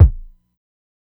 ILLMD007_KICK_SAD_2.wav